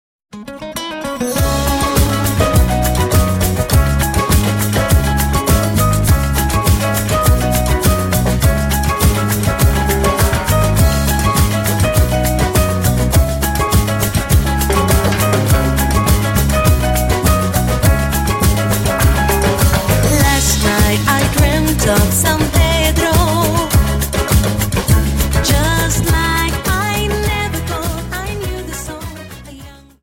Samba 51 Song